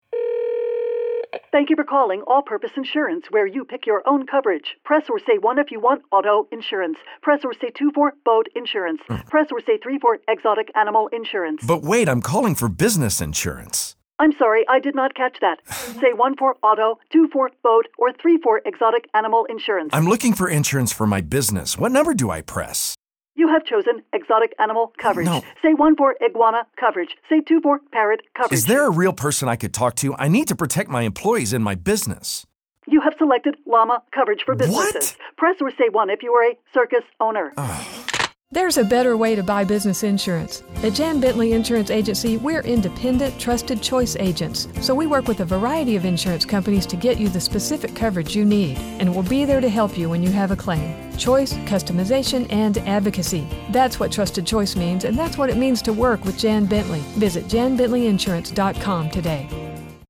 :60 Radio Spot “Sneezing” Commercial Lines 60